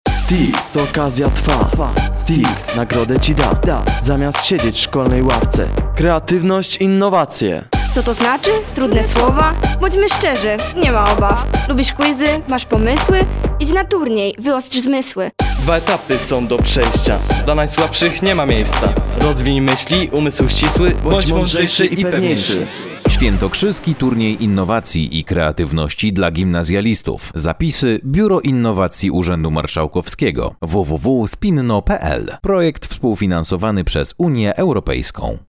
Spot radiowy STIK
162-spot-radiowy-stik